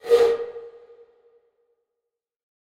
Звуки бамбука
Звук подувшего в бамбуковую флейту